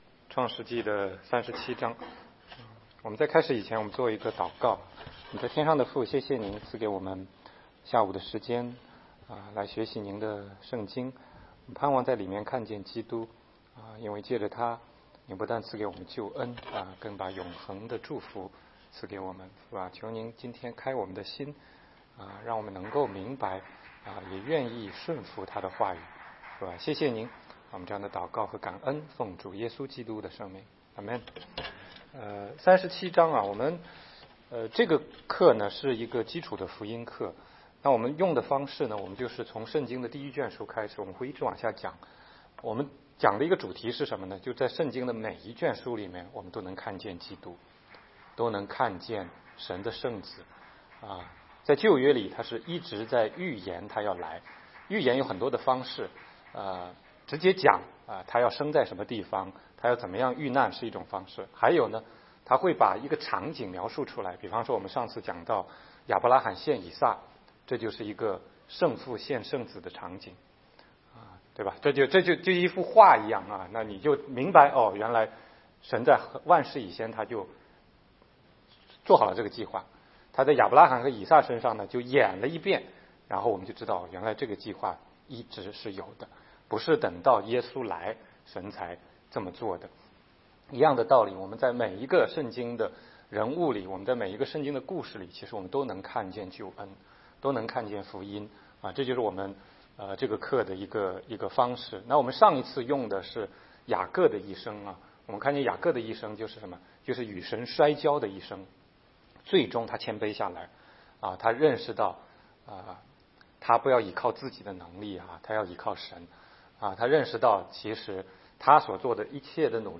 16街讲道录音 - 约瑟-耶稣的预表